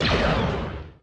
shot20.mp3